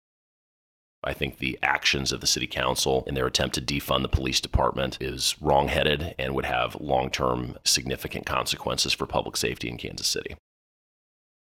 3. Senator Luetkemeyer also says, if his proposal passes, it would go to the voters.